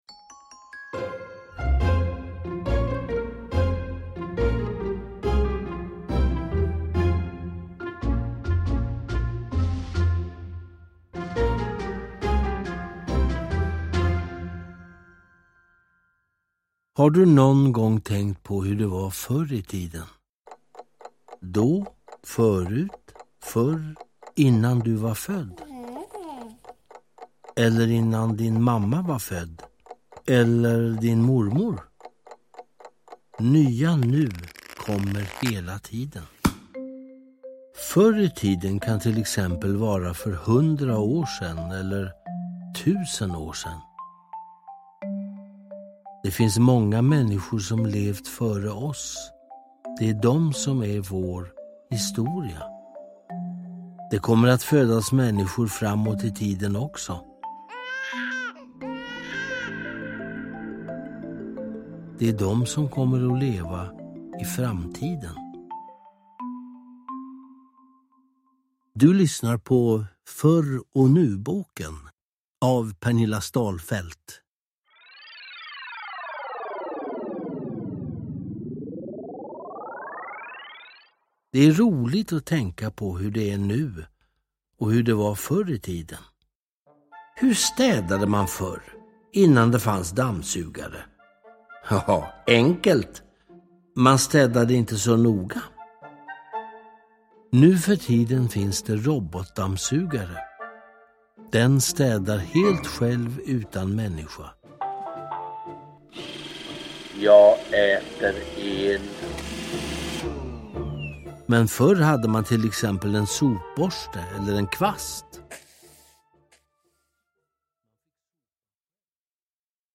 Förr och nu-boken – Ljudbok
Uppläsare: Peter Haber